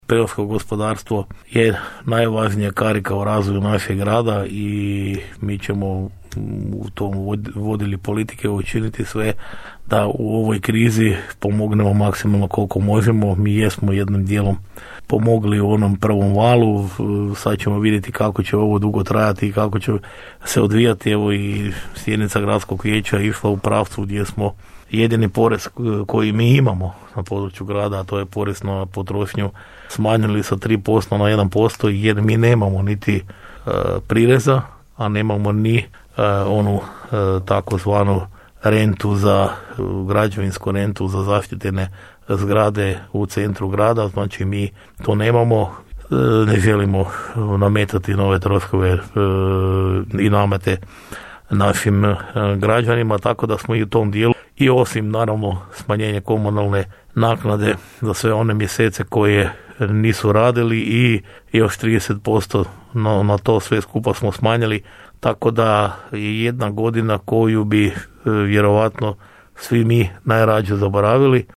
Gradonačelnik Ljubomir Kolarek u emisiji Osinjak